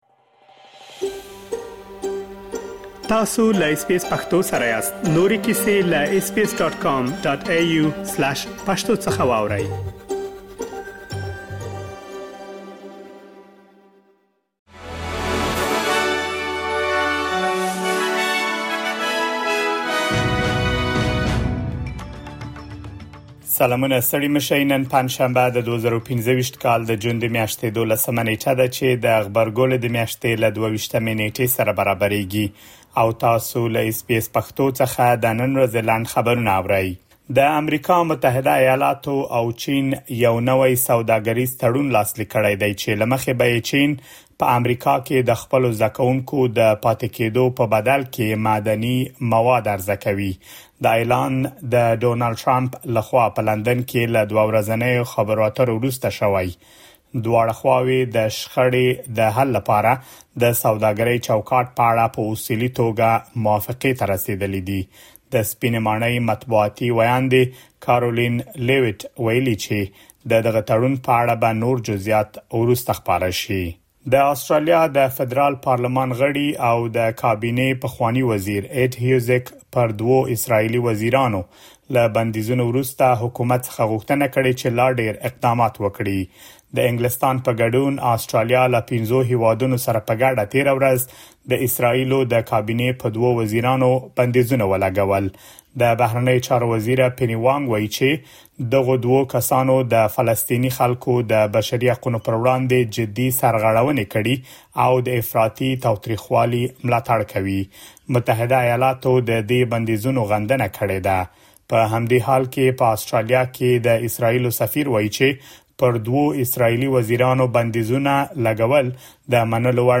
د اس بي اس پښتو د نن ورځې لنډ خبرونه | ۱۲ جون ۲۰۲۵